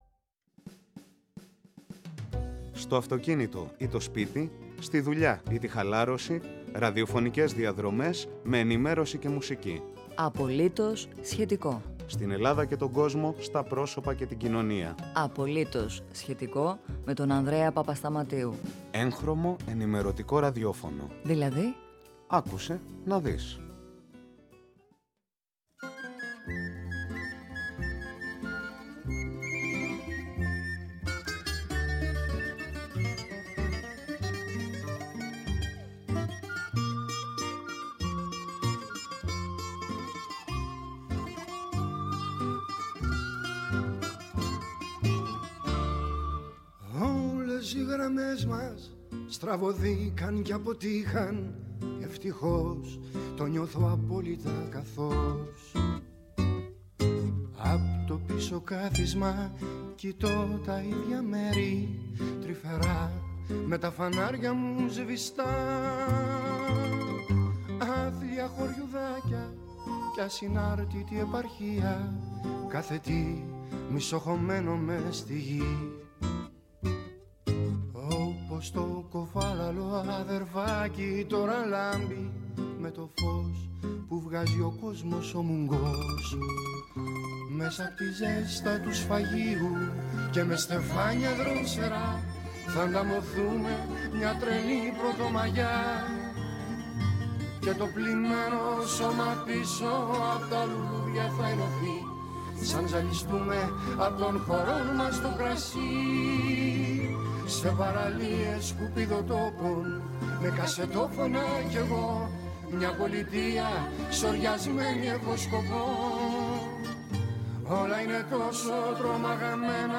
Απογευματινή ενημέρωση και ραδιόφωνο; «Απολύτως … σχετικό»!